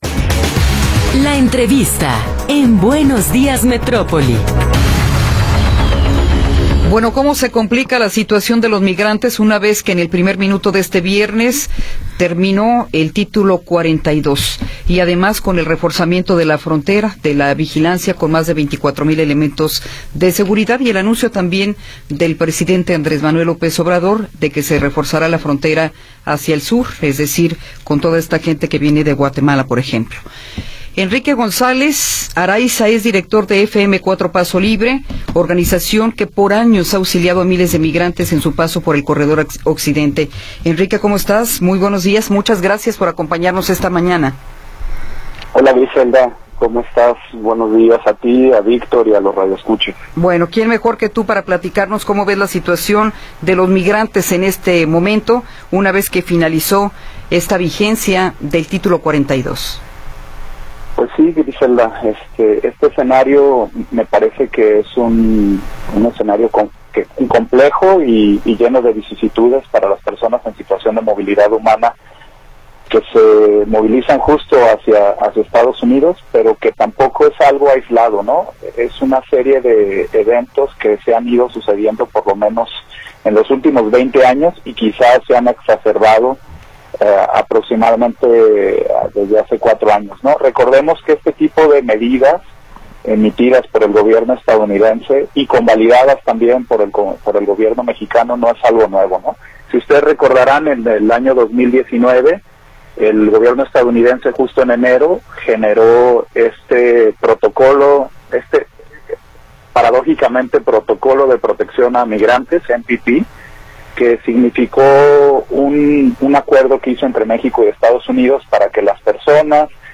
entrevista-4.m4a